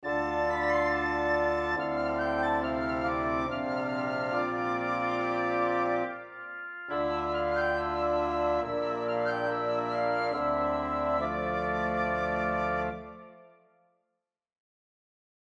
Modern Classical Composer/Artist